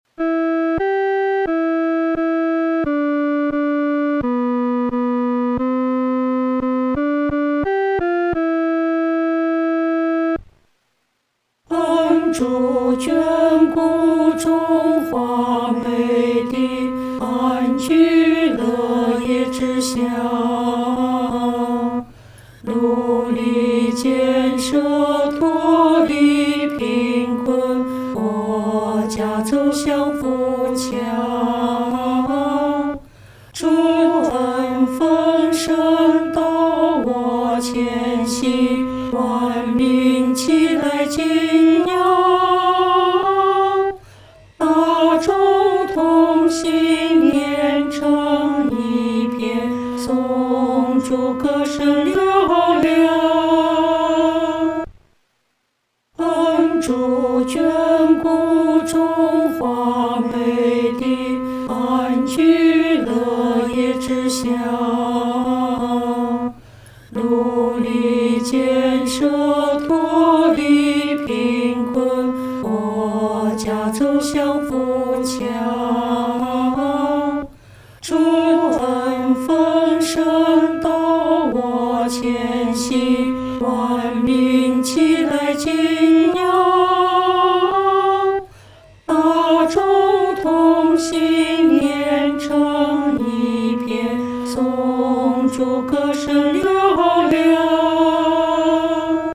合唱
女低